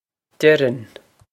Pronunciation for how to say
Dir-inn
This is an approximate phonetic pronunciation of the phrase.